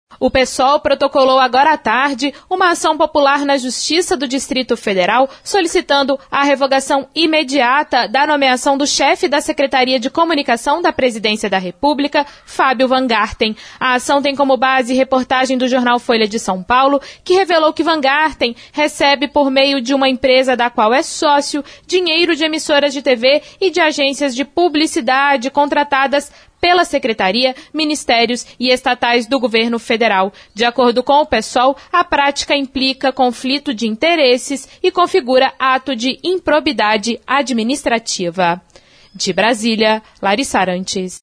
O secretário especial de Comunicação Social da Presidência da República, Fabio Wajngarten, fez nesta quarta-feira, 15 um pronunciamento, no Palácio do Planalto, em resposta à matéria veiculada pelo jornal Folha de S.Paulo, que aponta suposto conflito de interesse na participação dele como sócio em uma empresa de marketing.